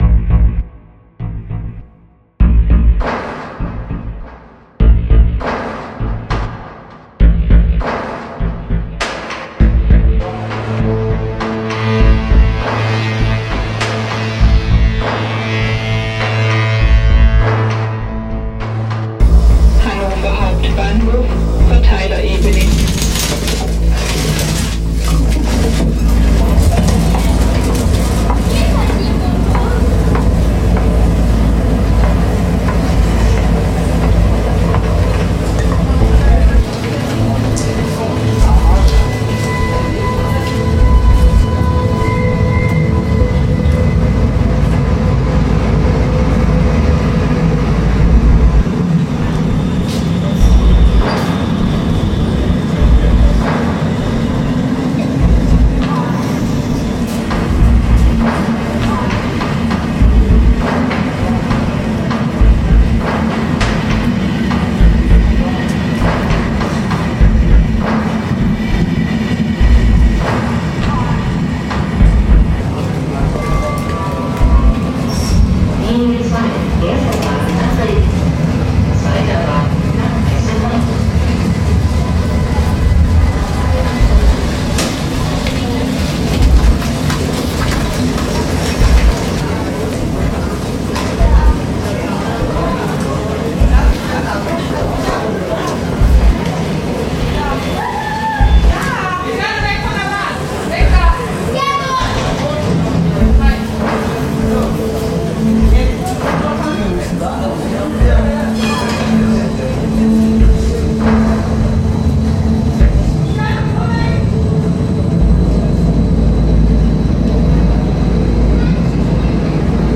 The Hannover Hauptbahnhof reimagined with bass pulses and a sense of something important about to take place.